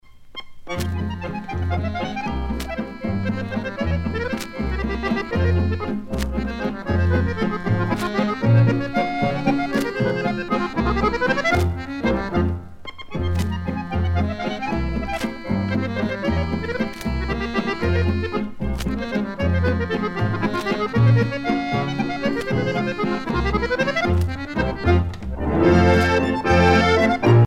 danse : valse musette
Pièce musicale éditée